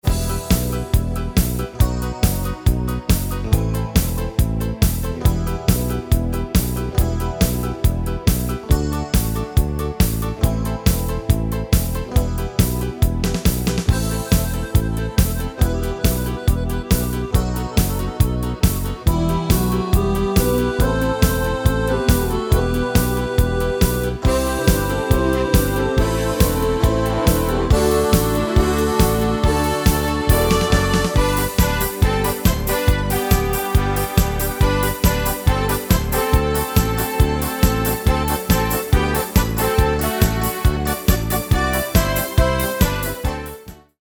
Demo/Koop midifile
Genre: Nederlands amusement / volks
Toonsoort: G/A
- Vocal harmony tracks